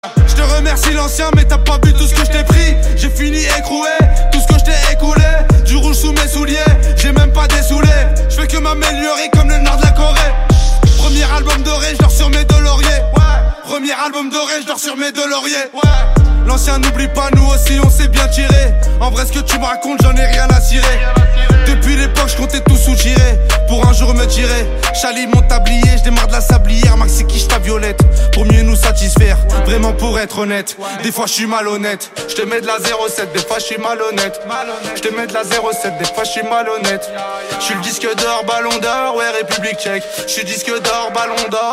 Classique